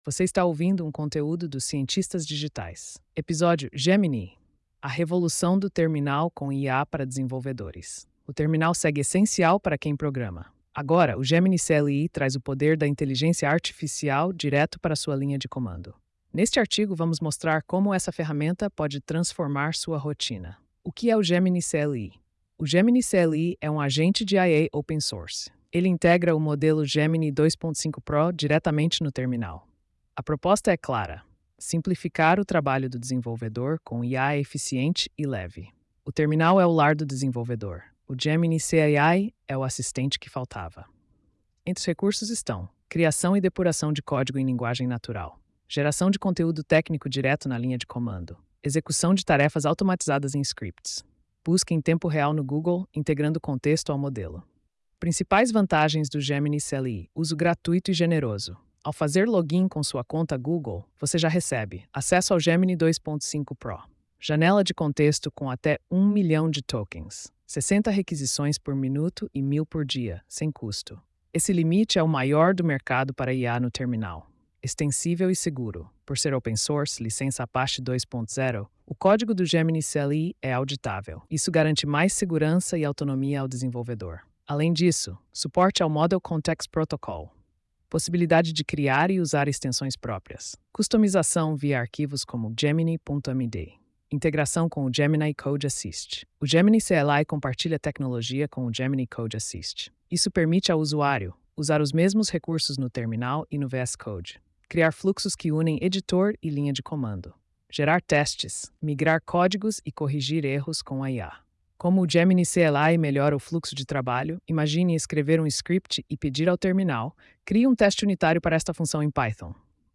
post-3216-tts.mp3